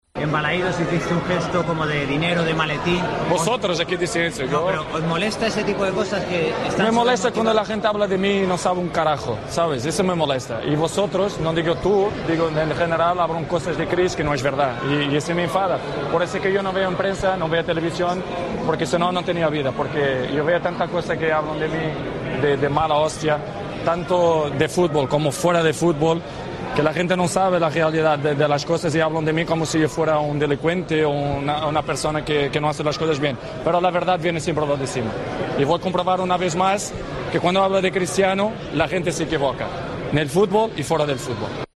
El portugués mostró su enfado hablando en la zona mixta: "Me molesta cuando la gente habla de mí y no sabe un carajo.